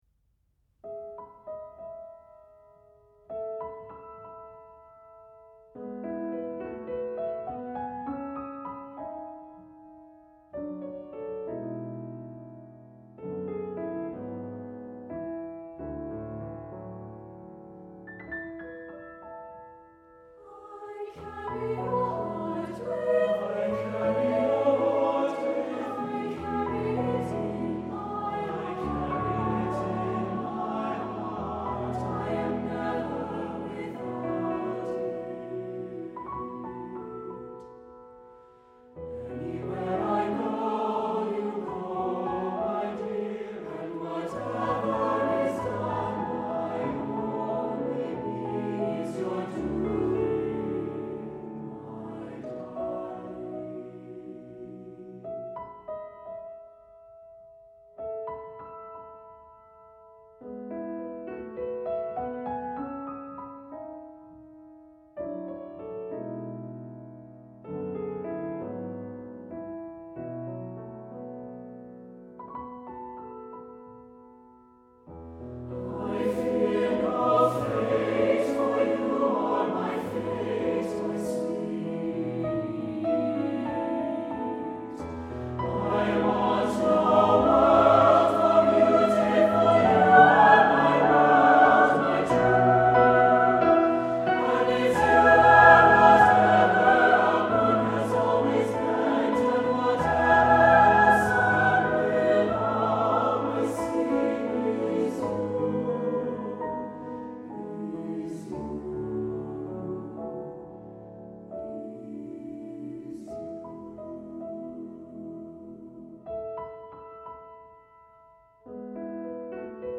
Accompaniment:      With Piano
Music Category:      Choral
Here is an impressive, yet romantically intimate, piece.